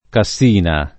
kaSS&na] top. (Lomb.) e cogn. — es. del toponimo: Cassina de’ Pecchi [